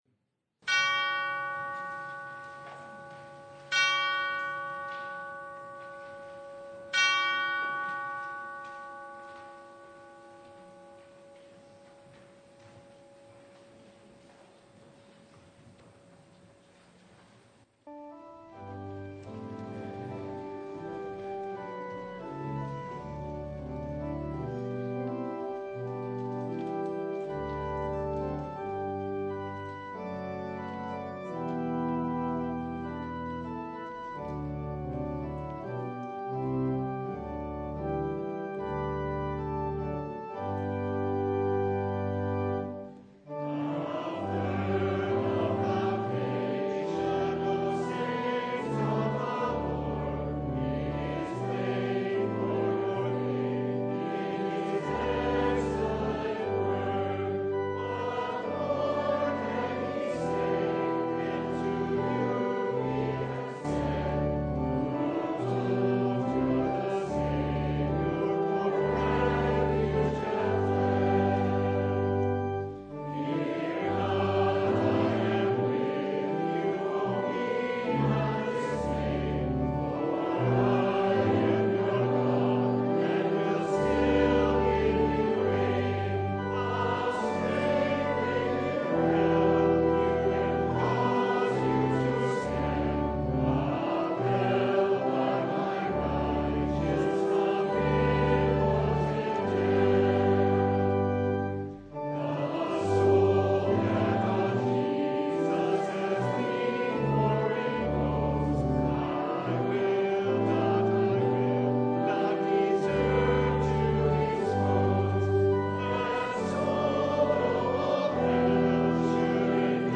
Service Type: Sunday
Full Service